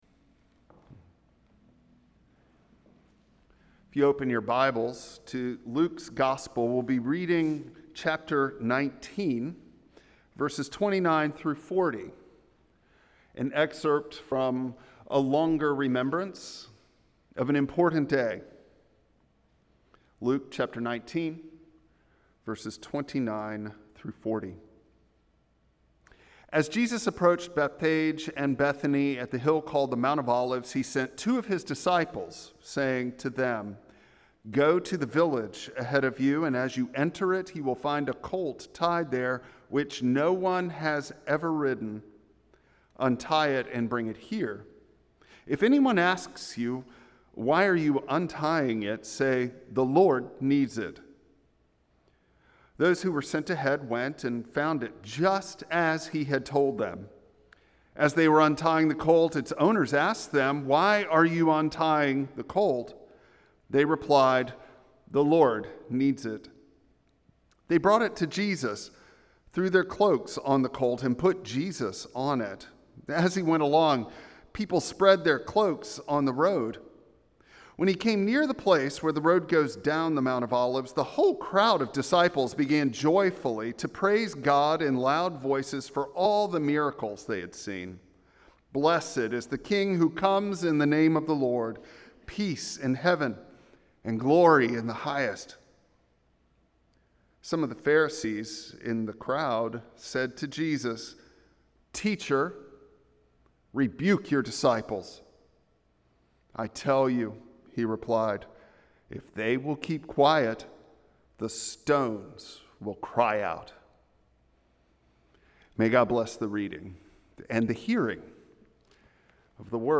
Luke 19:29-40 Service Type: Traditional Service Bible Text